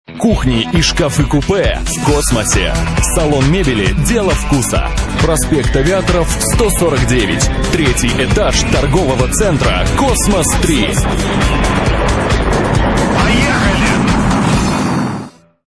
Рекламные радио ролики записаны в формате mp3 (64 Kbps/FM Radio Quality Audio).